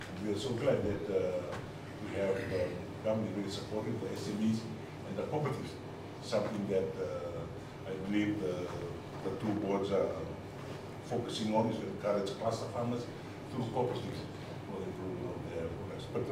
Speaking at the re-launch of the President’s Coconut and Rice Farmers Awards, he says the two sectors have their own potential, but there is much more that needs to be done.